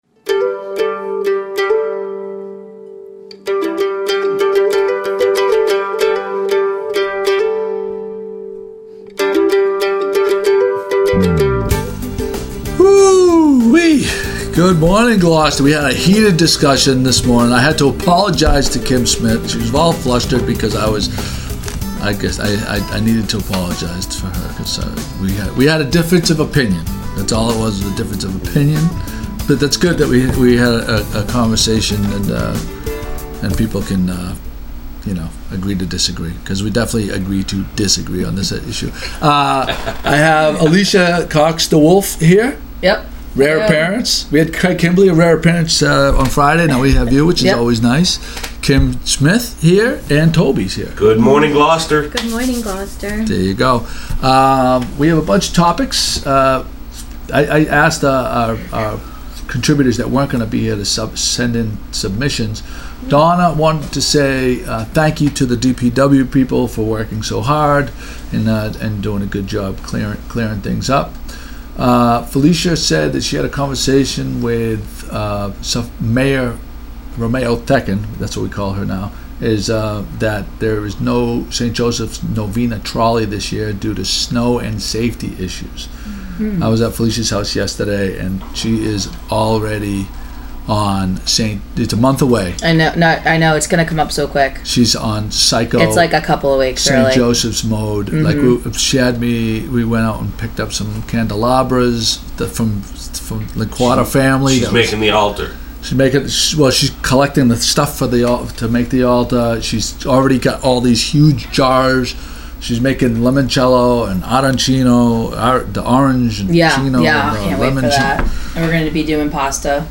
With Guests